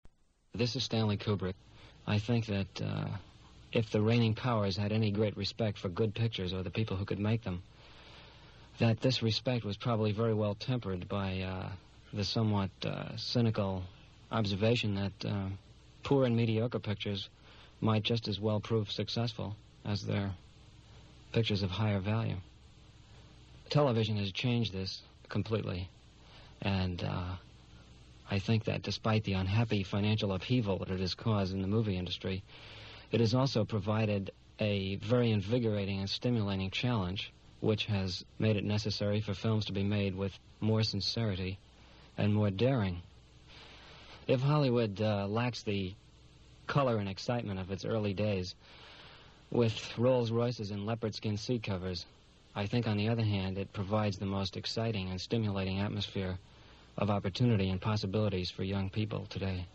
Interview
CBS Radio, December 1958